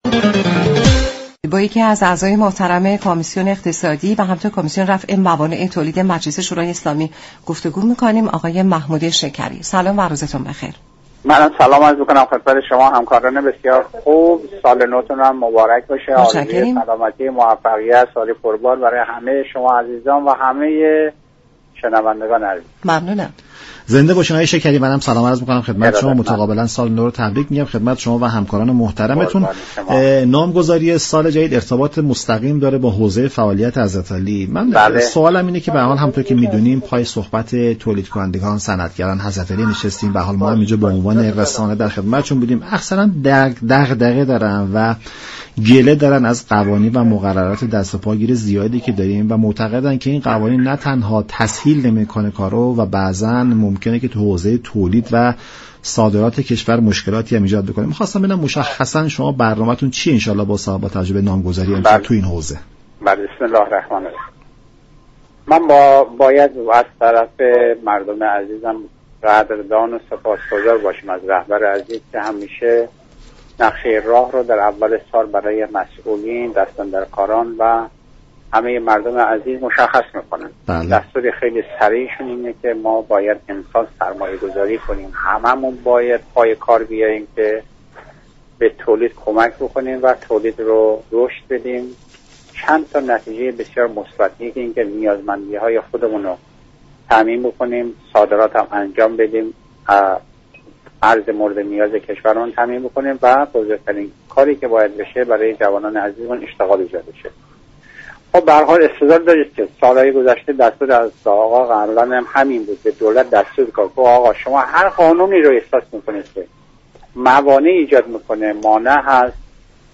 عضو كمیسیون اقتصادی مجلس شورای اسلامی در گفت و گو با رادیو ایران گفت: امروز كشور با جنگ اقتصادی روبروست، و افزایش میزان تولید، تنها راه مبارزه است.